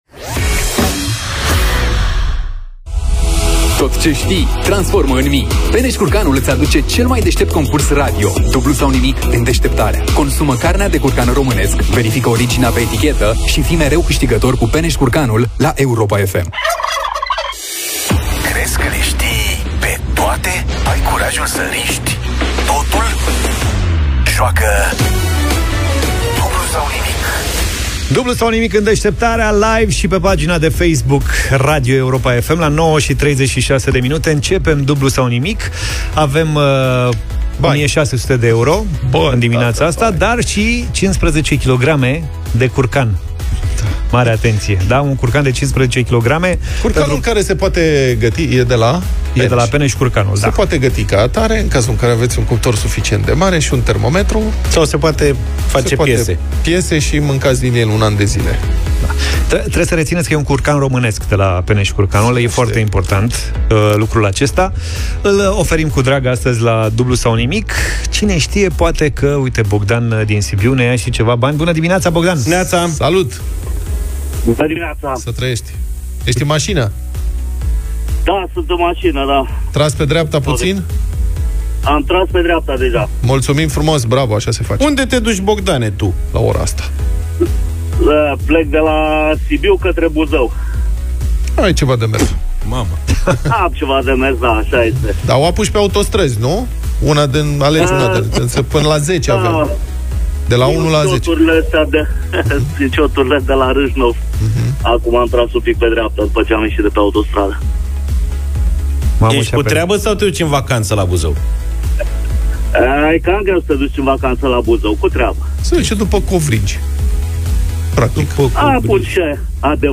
Ca de fiecare dată, concursul a fost în direct la radio, dar și video live pe pagina de Facebook Radio Europa FM.